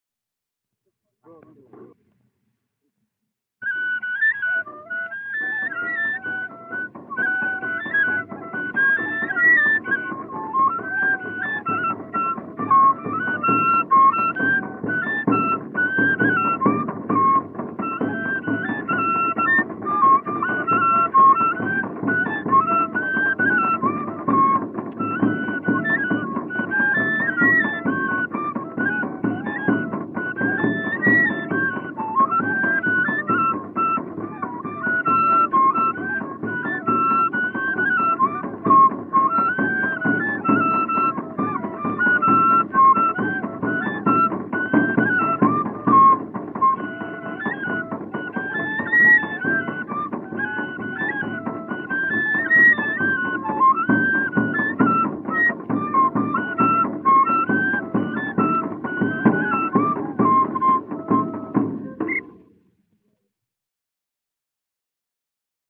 Marcha (instrumentos)